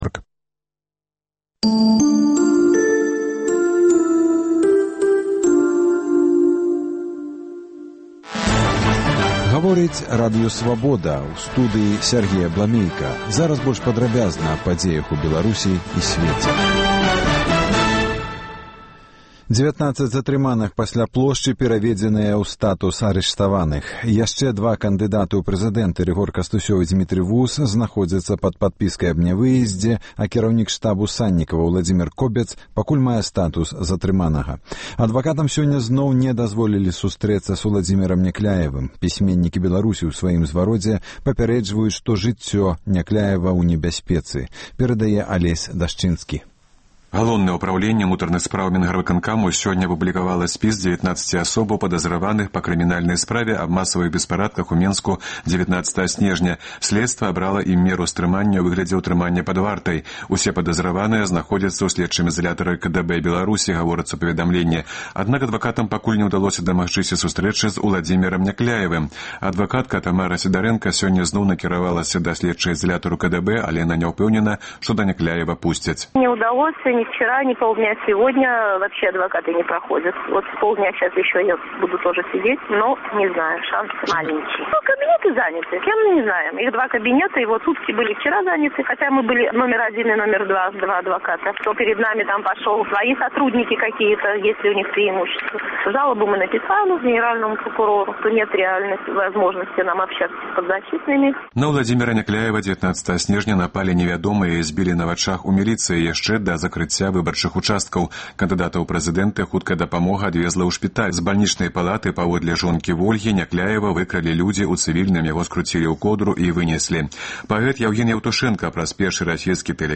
Паведамленьні нашых карэспандэнтаў, званкі слухачоў, апытаньні ў гарадах і мястэчках Беларусі.
Званкі на Свабоду.